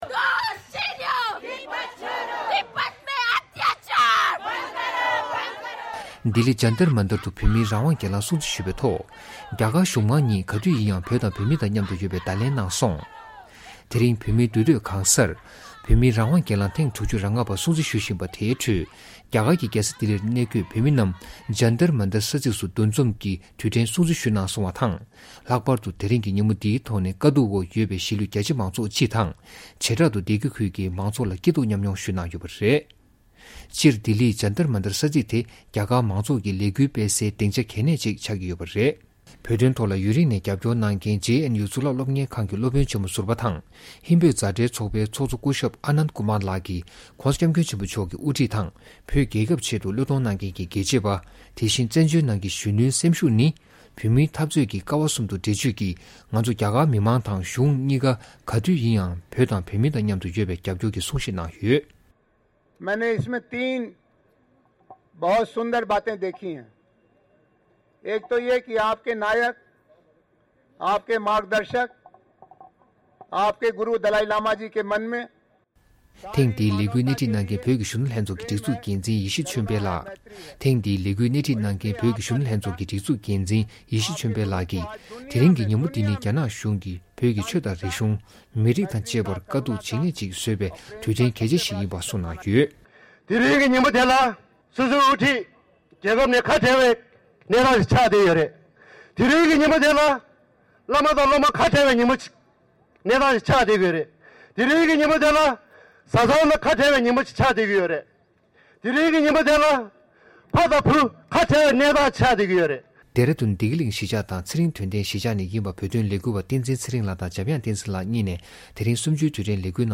དེ་རིང་གི་ཉིན་མོ་འདིར་བོད་གར་བསམ་གླིང་སོགས་ནང་གི་ཟ་ཁང་དང་ཚོང་ཁང་ཡོངས་རྫོགས་སྒོ་བརྒྱབ་པ་དང་། ས་གནས་སུ་གནས་འཁོད་བོད་པ་ཆིག་སྟོང་བརྒལ་བས་ཚོར་ཤུགས་དྲག་པོས་རྒྱ་གཞུང་གི་དྲག་པོའི་བཙན་འཛུལ་ལ་ངོ་རྒོལ་སྐད་འབོད་གནང་ཡོད་པ་རེད།